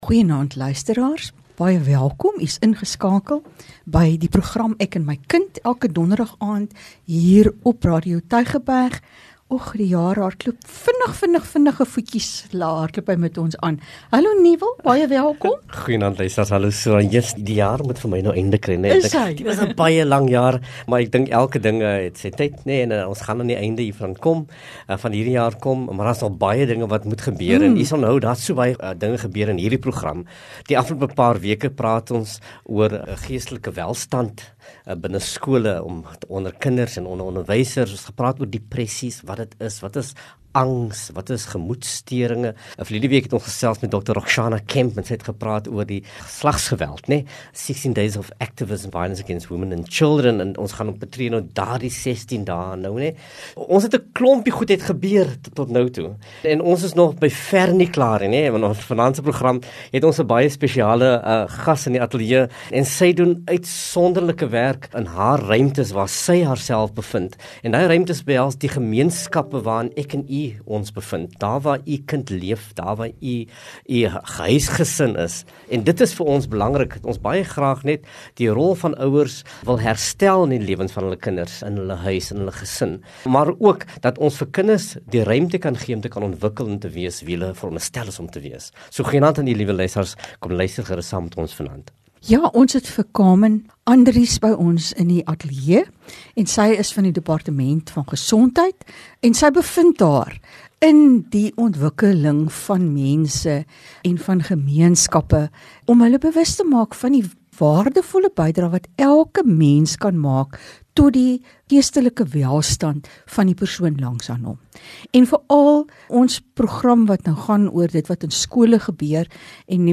“Ek en my kind” is gespreksprogram wat vanuit ‘n onderwysperspektief ouerleiding bied ten opsigte van kinderontwikkeling en kindersorg. Dit gee ouers perspektief oor Onderwyssake en brandpunte wat impak kan hê op hul rol en verantwoordelikheid ten opsigte van hul kind se welstand in die klaskamer en op die speelgrond.